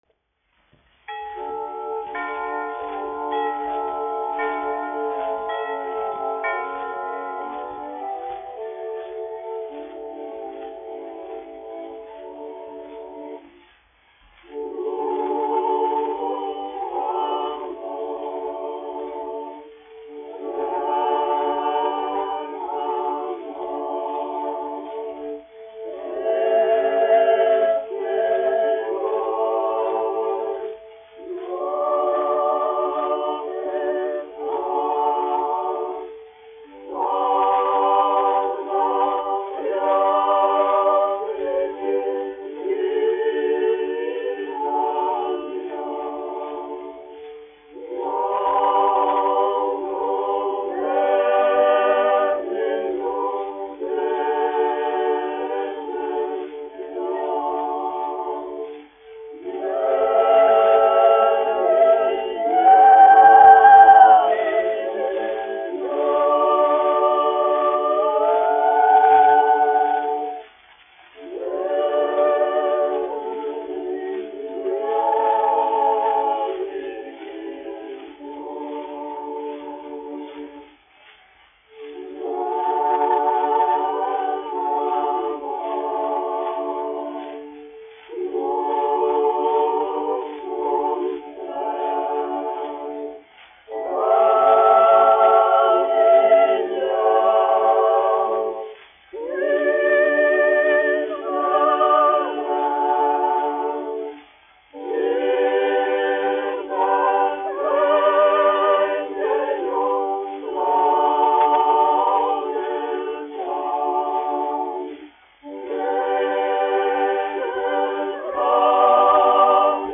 Rīgas Latviešu dziedāšanas biedrības jauktais koris, izpildītājs
1 skpl. : analogs, 78 apgr/min, mono ; 25 cm
Ziemassvētku mūzika
Skaņuplate